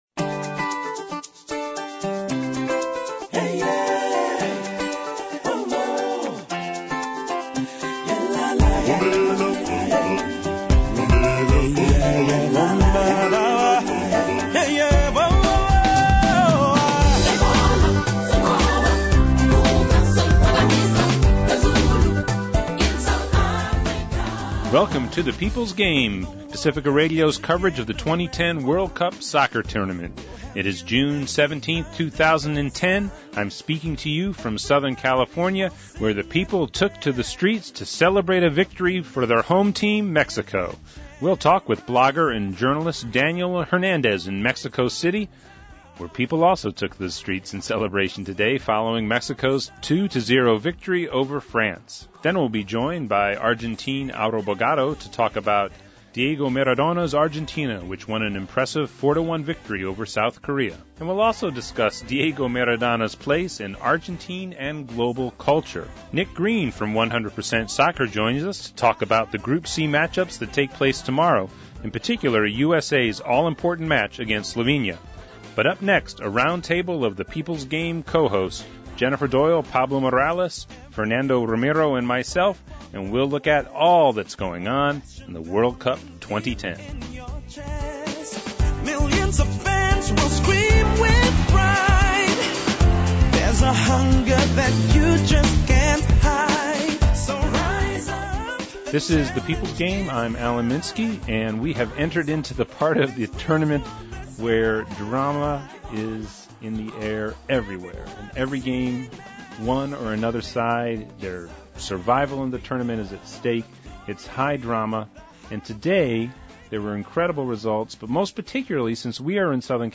a roundtable discussion leads off the show